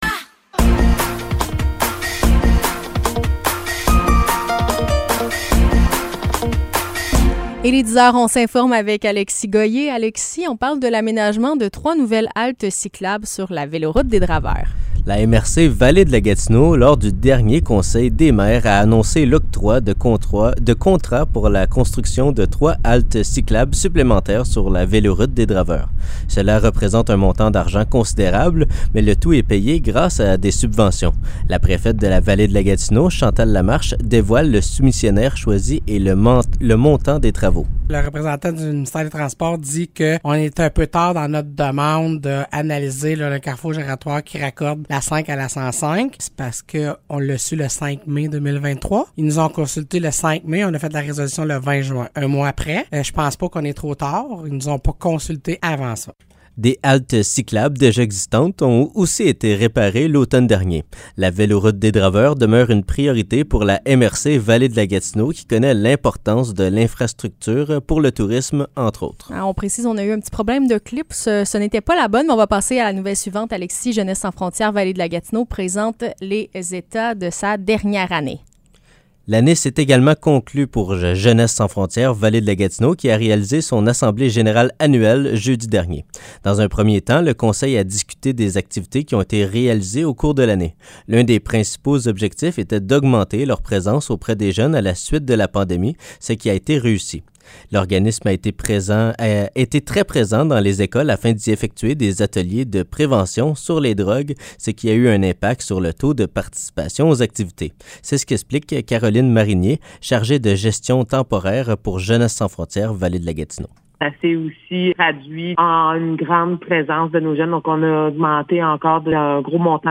Nouvelles locales - 5 juillet 2023 - 10 h